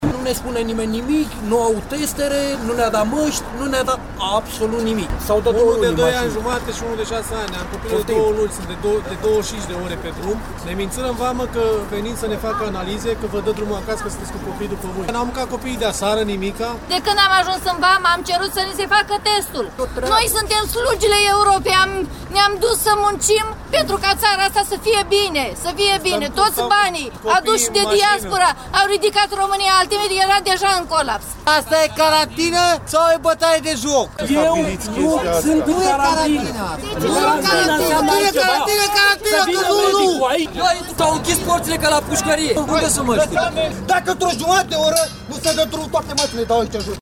02-VOXURI-CARANTINA.mp3